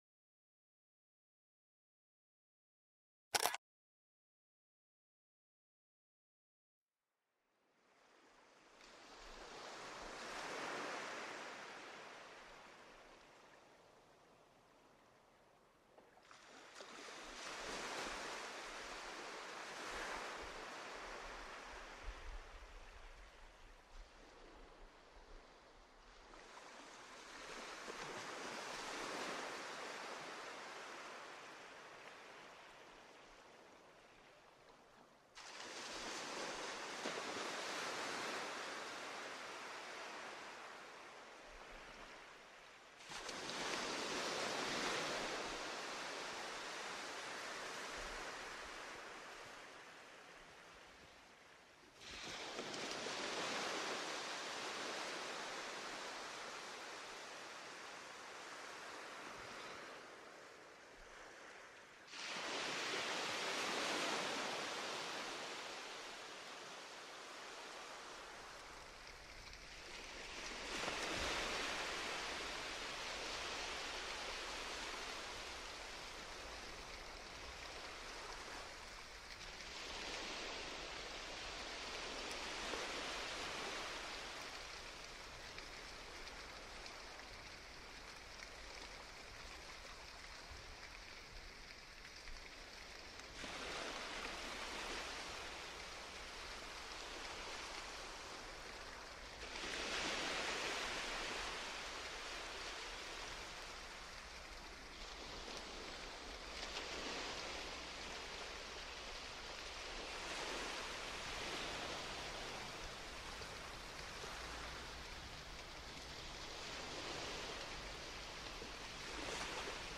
PERFEKTE ENTSPANNUNG: Meeresgewitter-Vereinigung mit Wellen + Wind